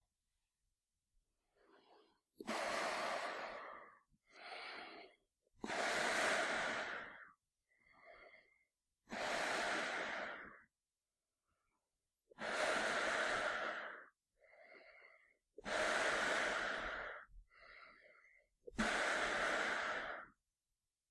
Звуки противогаза
Звук медленного дыхания в защитной маске